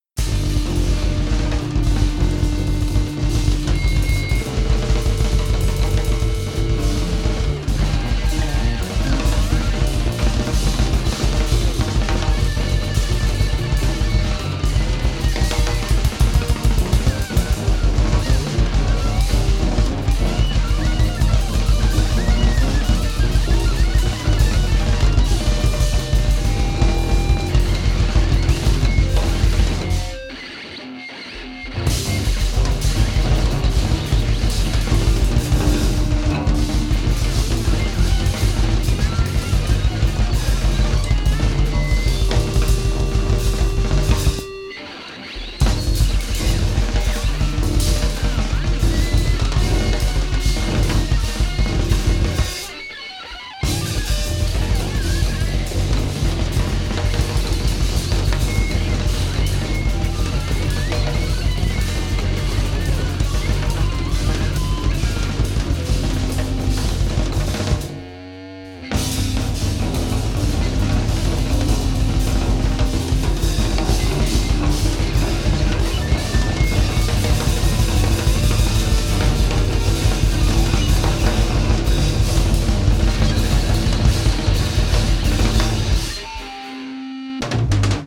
improvised music